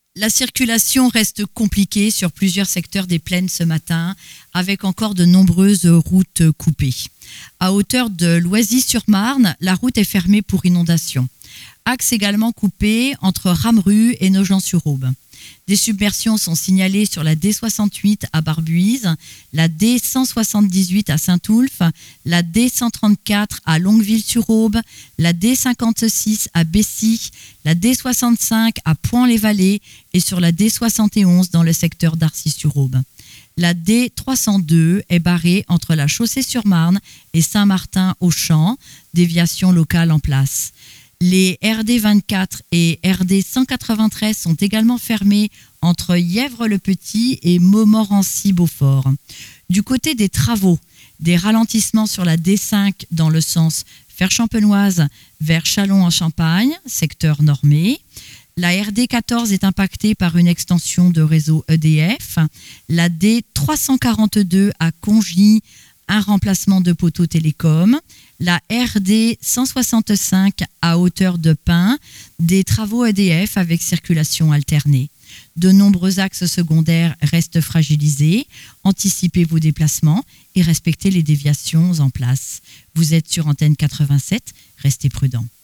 Bienvenue dans l’InfoRoute des Plaines – votre bulletin circulation du matin !